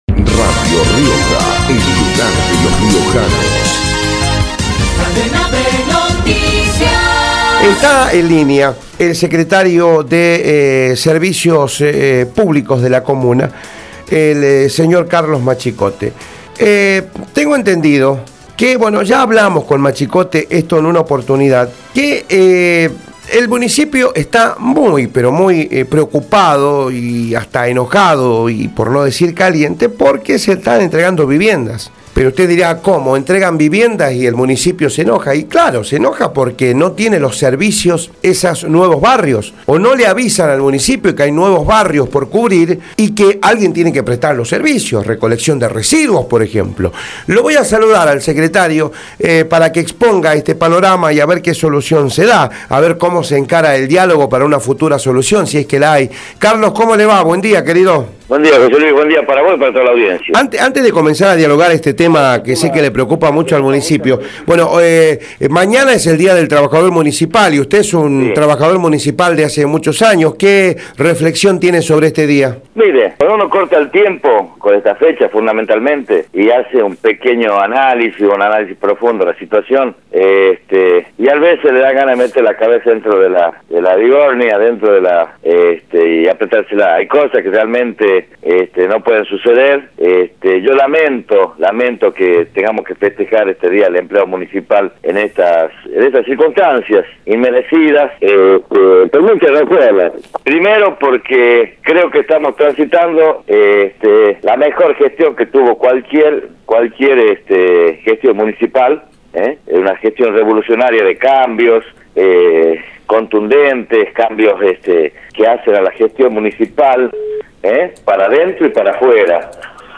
Carlos Machicote, secretario de Servicios Públicos, por Radio Rioja
carlos-machicote-secretario-de-servicios-pc3bablicos-por-radio-rioja.mp3